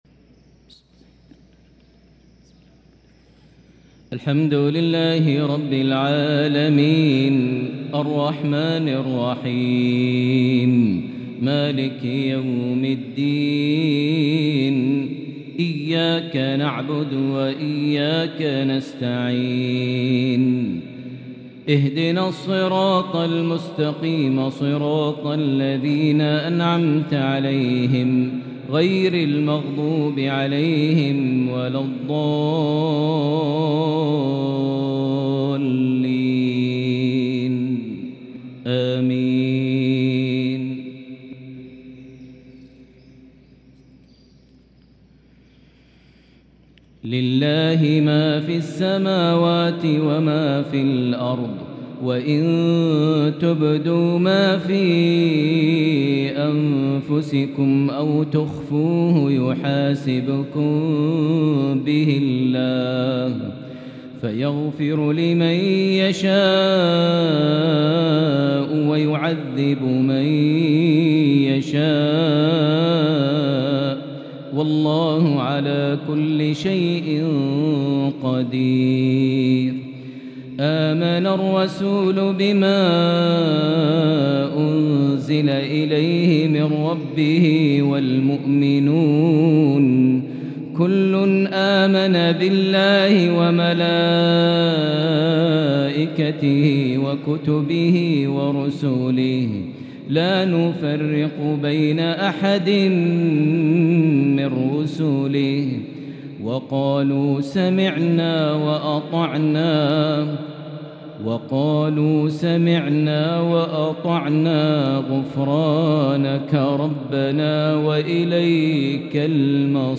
تلاوة راائعة لأخر سورة البقرة { 284-286} | مغرب الأحد 8-2-1444هـ > 1444 هـ > الفروض - تلاوات ماهر المعيقلي